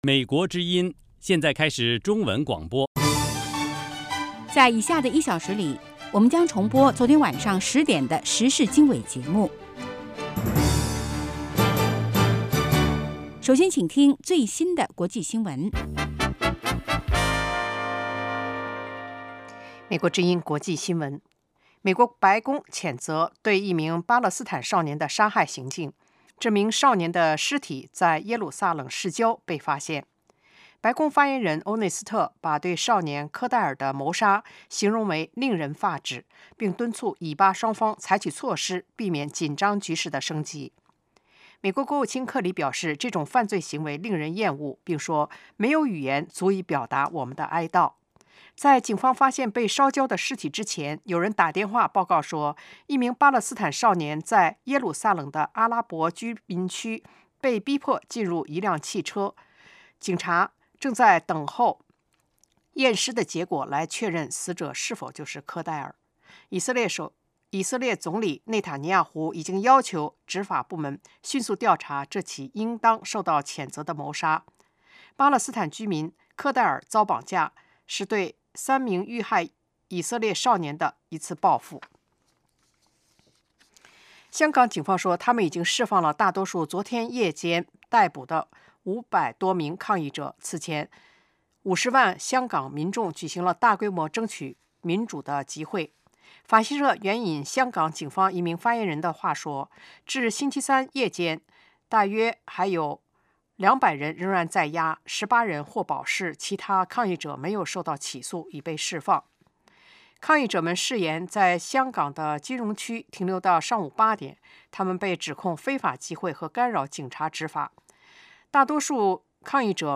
国际新闻 时事经纬(重播) 北京时间: 上午6点 格林威治标准时间: 2200 节目长度 : 60 收听: mp3